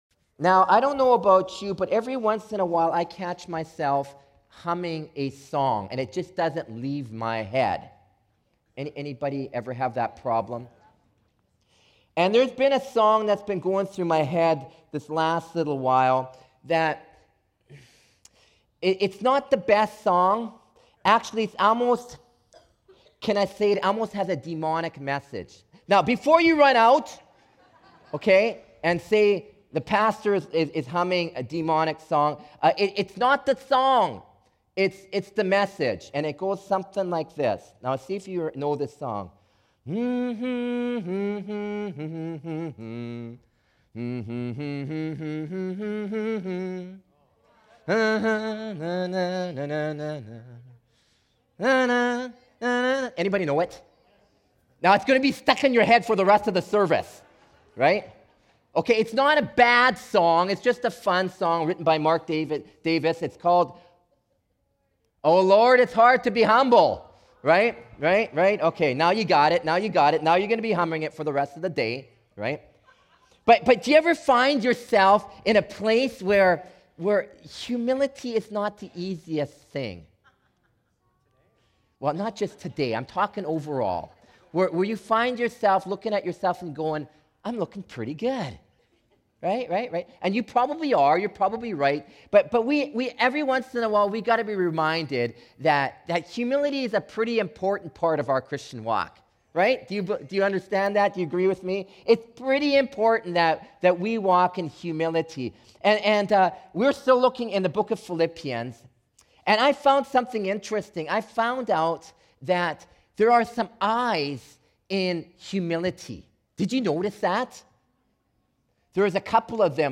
Sermons | Surrey Pentecostal Assembly